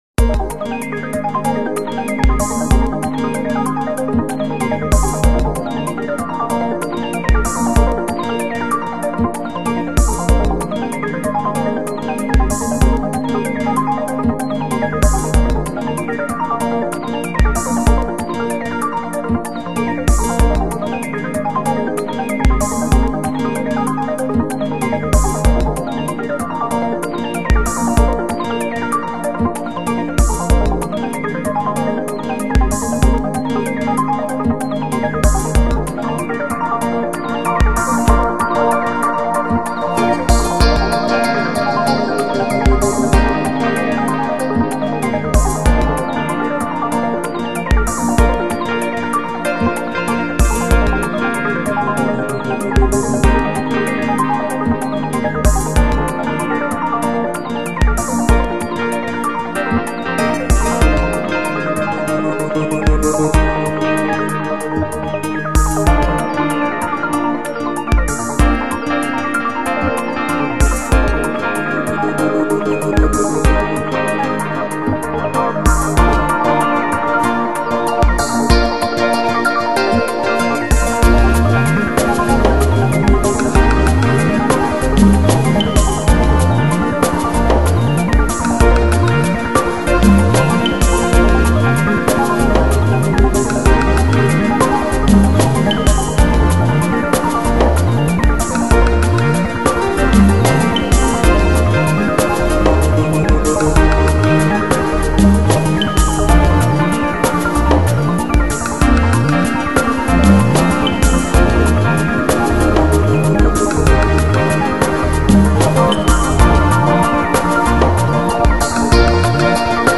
Genre: Downtempo, Lounge, Chill Out, Ambient
is based on deep house, acid jazz, funk and downbeat music
acoustic guitar and saxophone